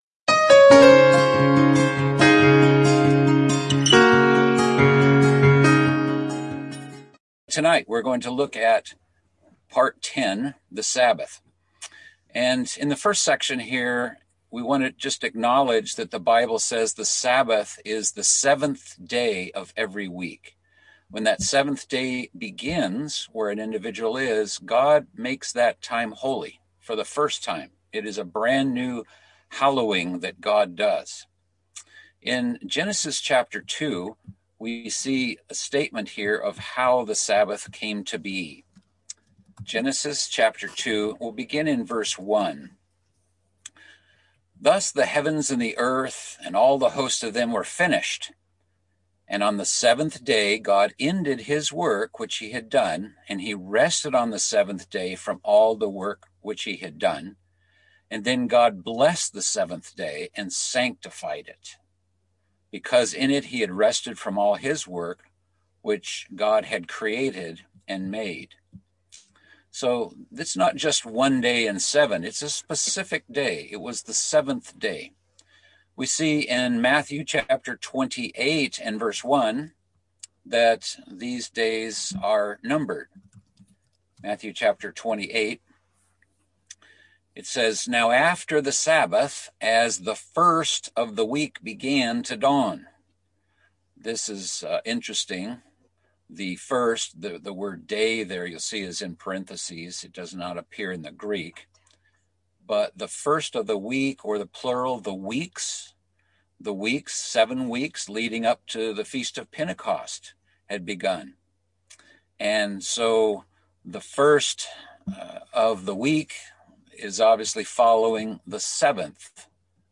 Fundamental Biblical Beliefs - Bible Study - Part 10 - The Sabbath